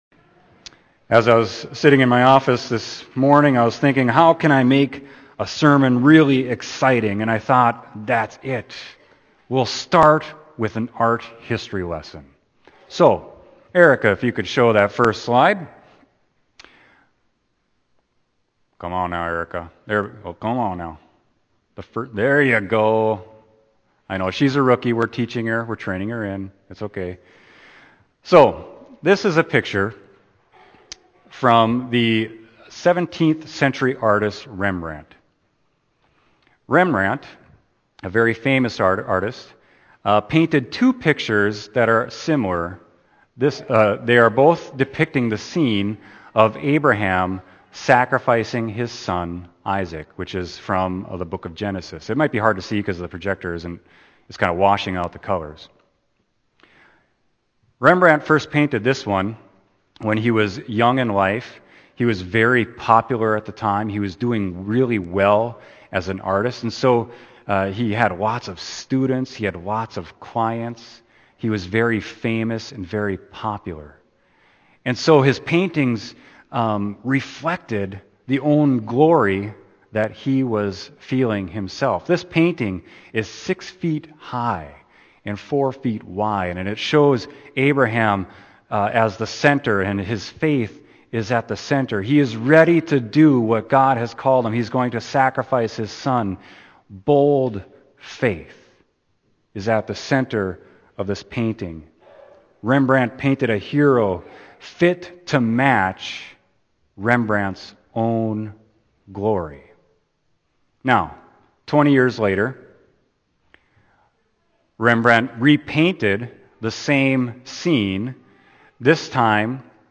Sermon: Luke 22.24-29